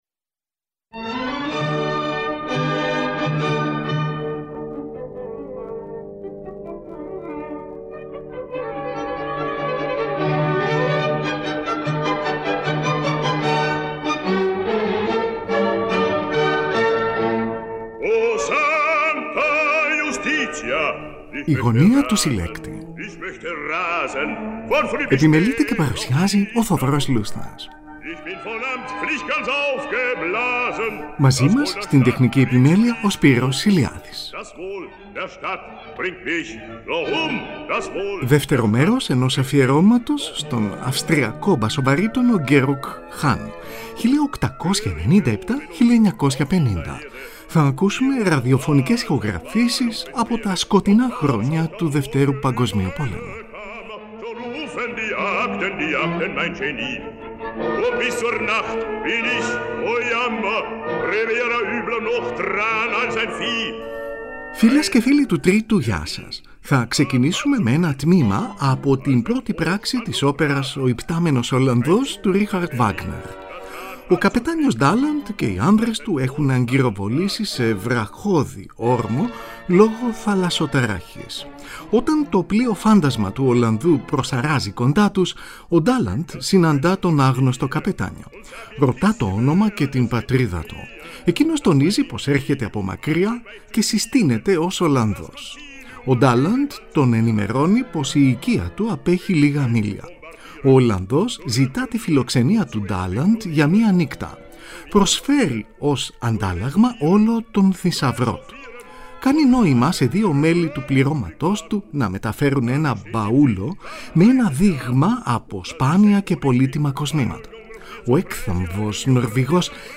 Ακούγονται αποσπάσματα από όπερες των Ludwig van Beethoven και Richard Wagner, καθώς και lieder για φωνή και πιάνο του Robert Schumann, από ραδιοφωνικές ηχογραφήσεις.